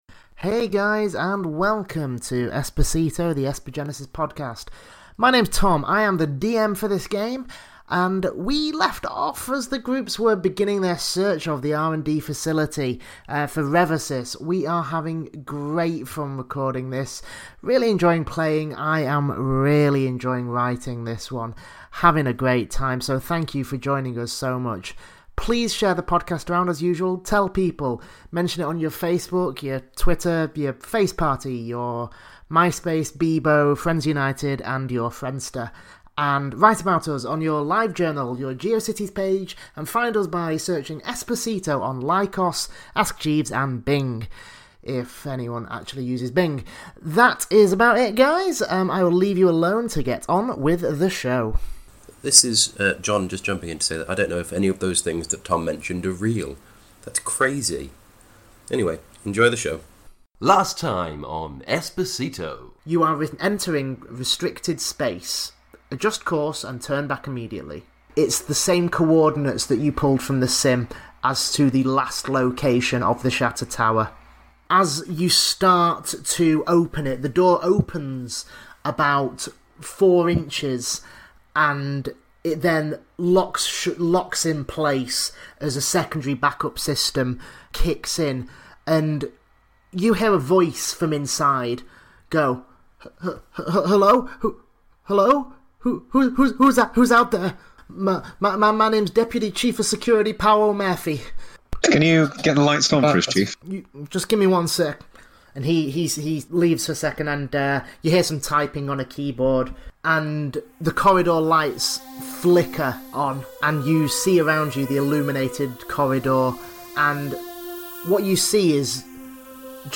This, the first live action Esper Genesis podcast, tells the ongoing saga of The Epsilon Shift, an innocent group of idiots who have been framed for a terrorist attack.